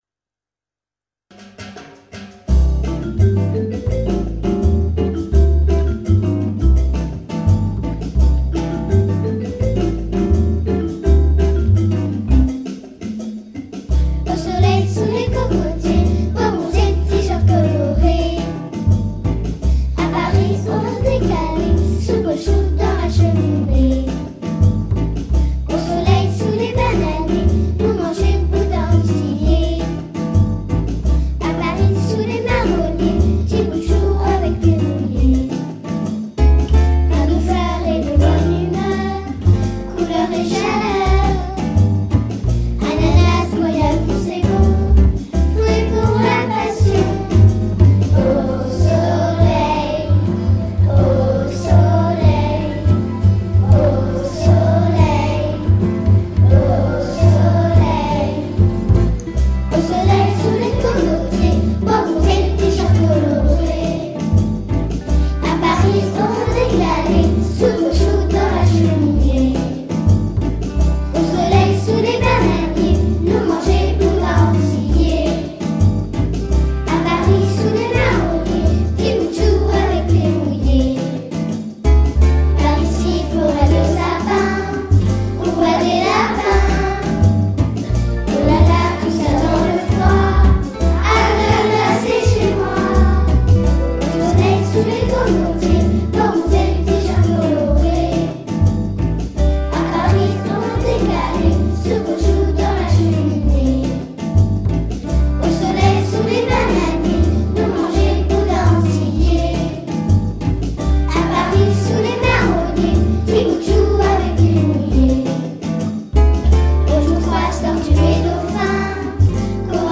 à la guitare.
enregistrement de travail